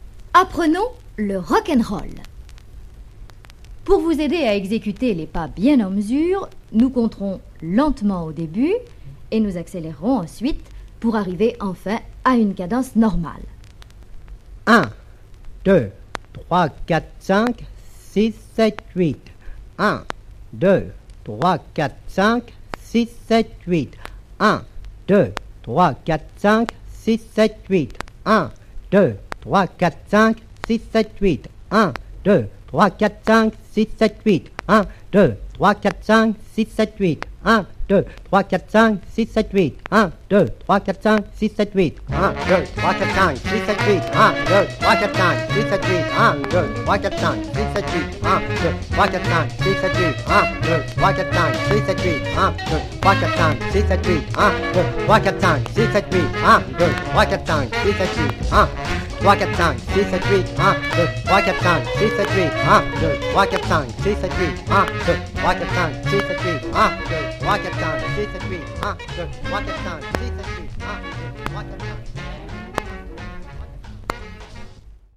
Avec sa voix légèrement chevrotante, c'est presque surréaliste. A tel point que je me suis amusé à vous faire un petit montage centré sur cet extrait.